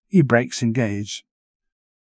e-brakes-engaged.wav